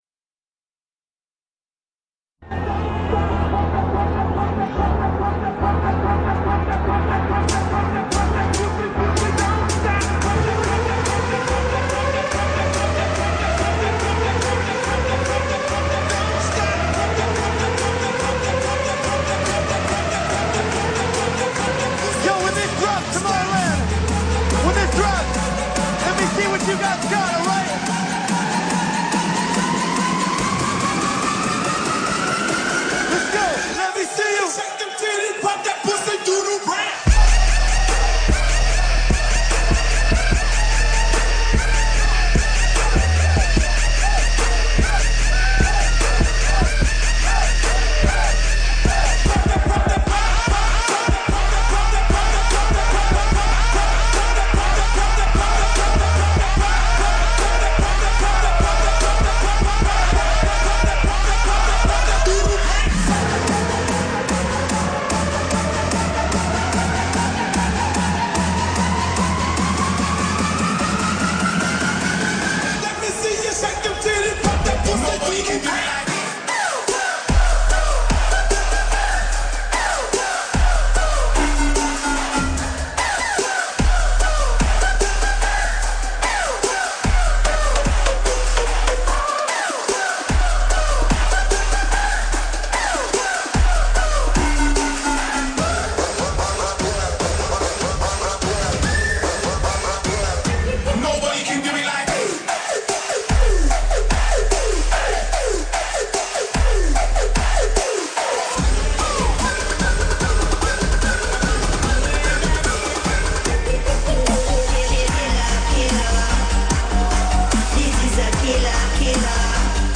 Liveset/DJ mix